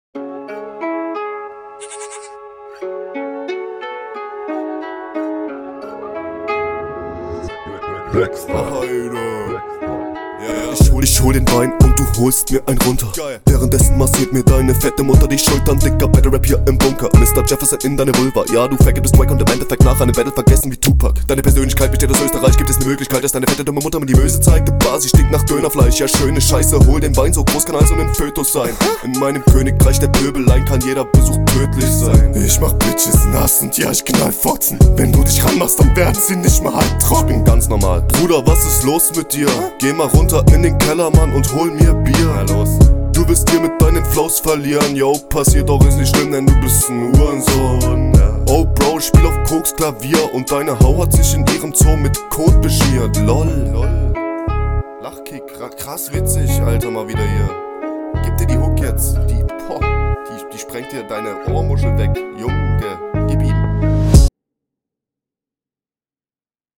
Flow: sick. kann ich gar nicht mehr zu sagen.
Flow: Kreativer und sauberer Flow, angenehme Betonungen Text: interessanter Text, lustig geschrieben und auf den …